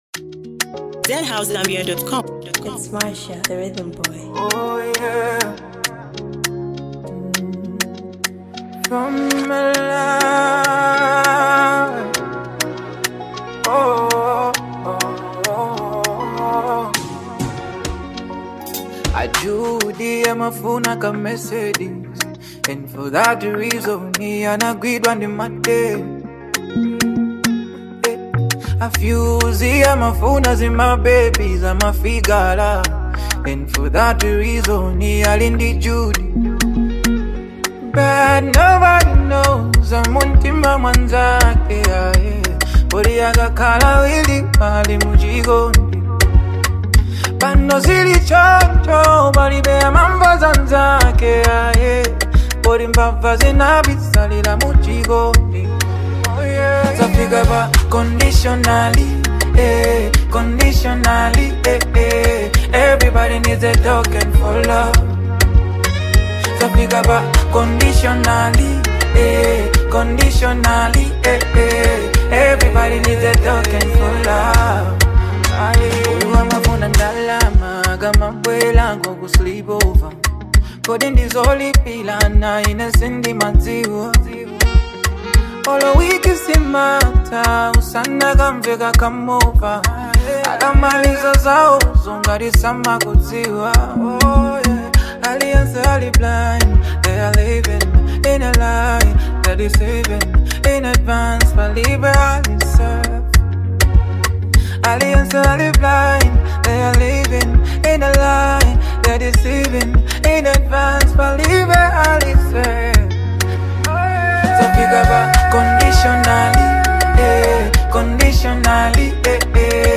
With smooth vocals and deep lyrics